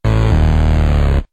failOrginal.ogg